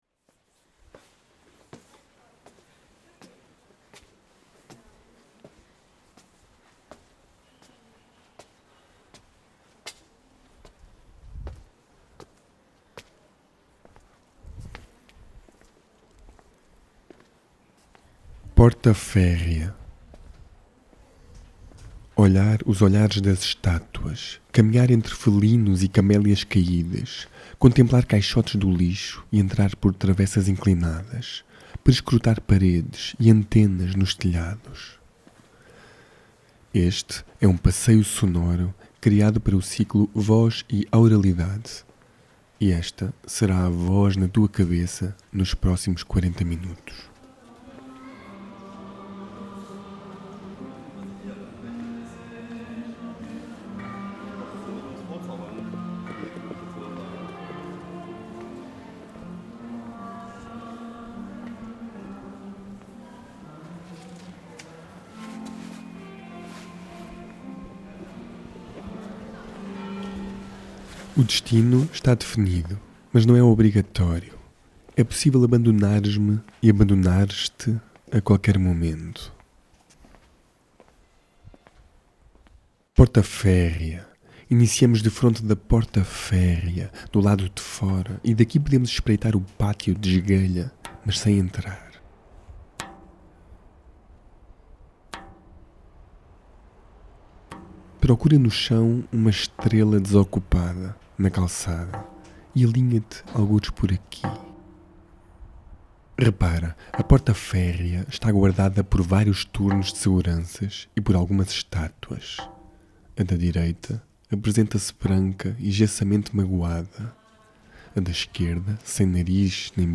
O formato sound walk segue o mesmo princípio, tentando articular esses dois espaços, aguçar a sua perceção através do seguinte paradoxo: ouvir melhor de ouvidos tapados. Não se trata de blindar os sentidos, mas de abrir a porta a outros devaneios, aos jogos de assincronia e à distorção ou anulação sonora das divisões entre exterior e interior: subitamente, as estátuas entram em cena. Há ainda uma voz intrusa que nos guiará nesta versão aural de “cabra-cega”, o que implica deixar entrar na cabeça referências estranhas e aceitar, por momentos, a partilha de outros passos que se tomam como nossos, seguindo trajetos (des)conhecidos de forma (des)orientada, como se encaixássemos em pegadas deixadas na areia ou nos metêssemos na pele do outro através dos ouvidos.